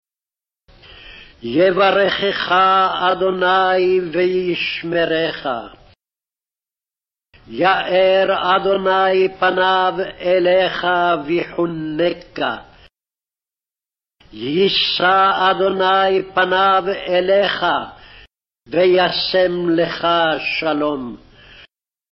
Slower Speed